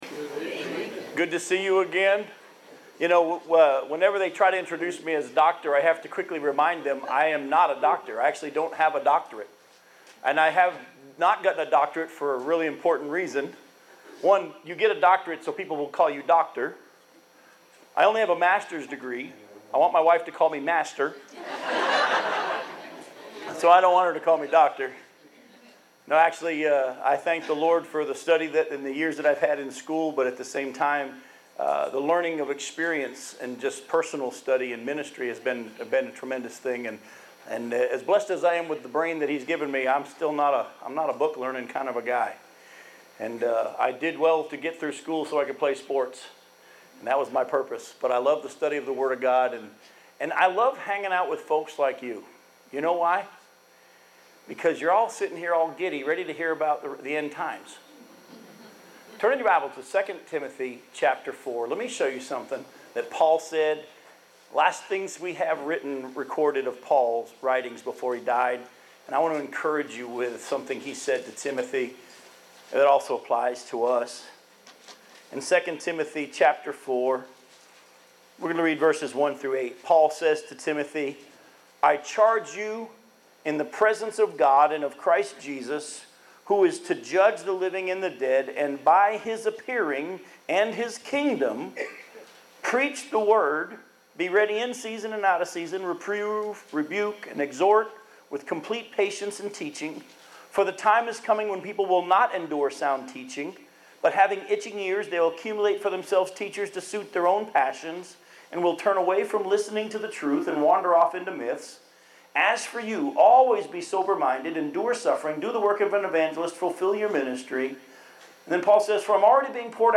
Justapreacher Ministries :: 2014 Prophecy Conference - Hillcrest Baptist Church